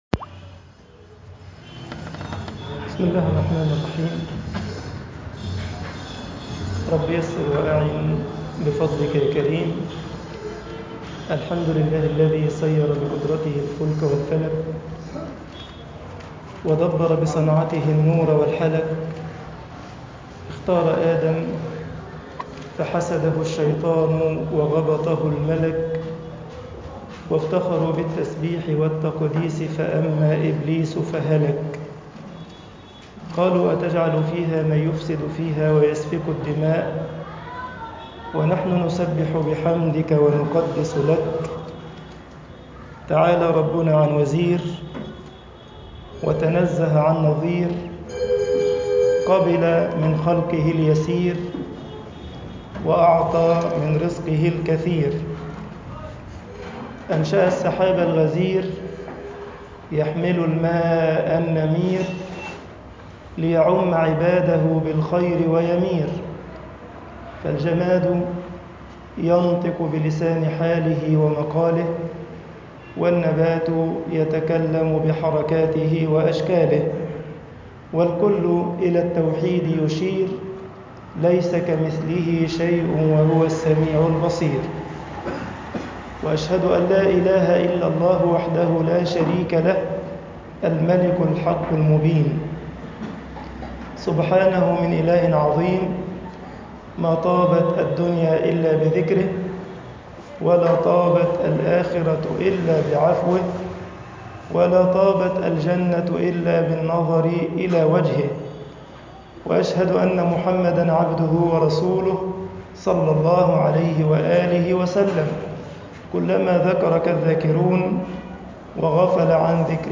درس 4